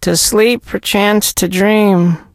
sandy_die_vo_03.ogg